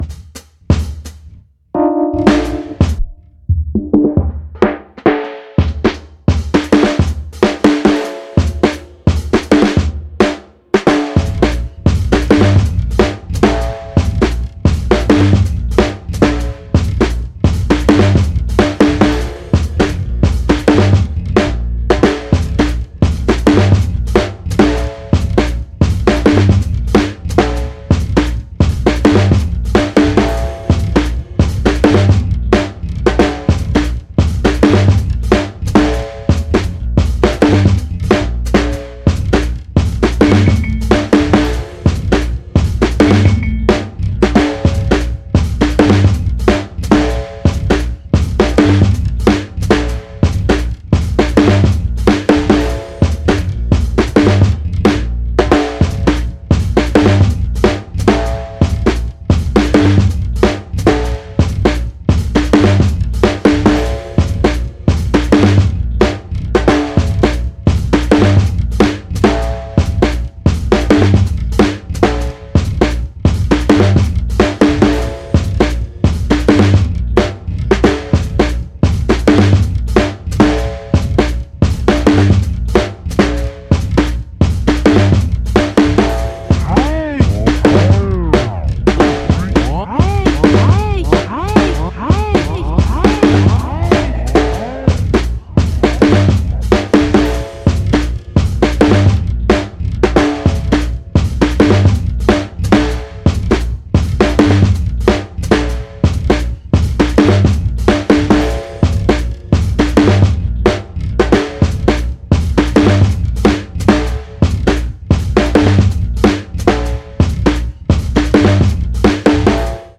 ビート、コラージュ、ノイズ、エレクトロニクスが混然となって展開される本作
ビート色強めで展開される多層的ミニマル作品！